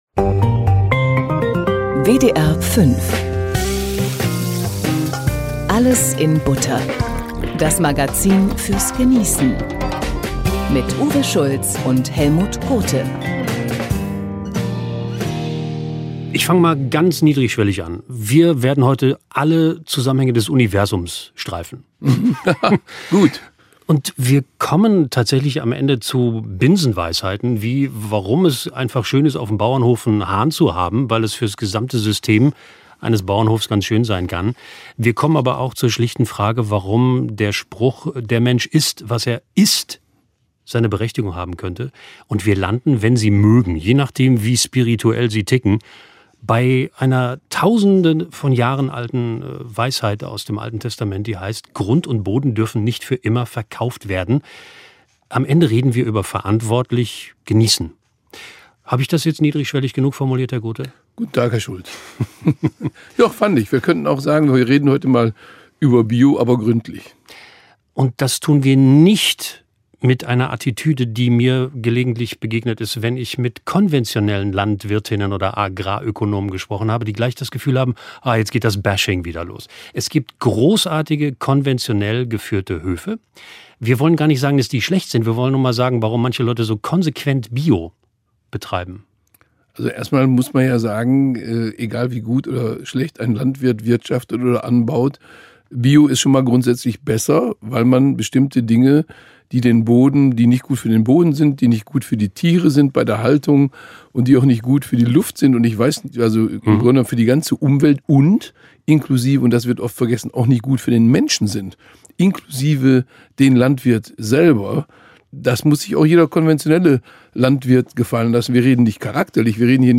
Darin werden Bio-Produkte getestet und Betreiber von Bio-Höfen interviewt. Der Beitrag ist sachlich aber gleichzeitig sehr locker, sodass er eine Empfehlung für wirklich jeden ist.